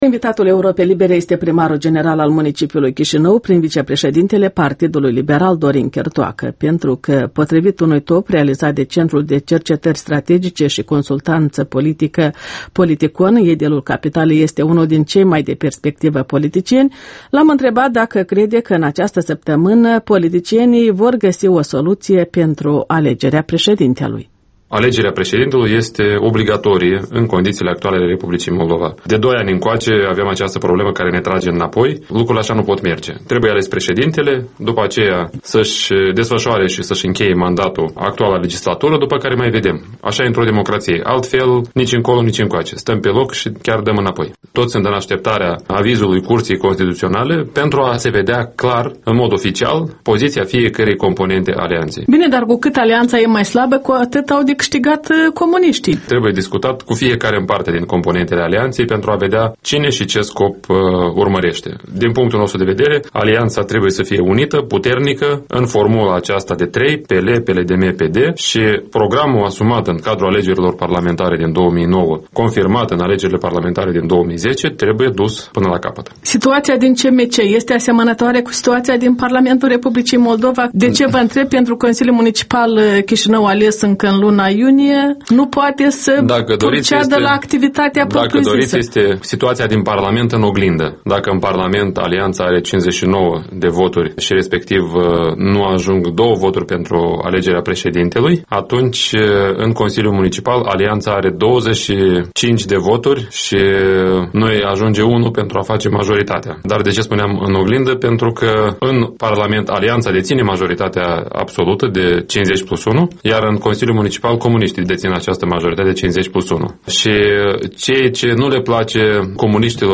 Primarul Dorin Chirtoacă la microfonul Europei Libere.
Interviul dimineții la Europa Liberă: cu primarul Dorin Chirtoacă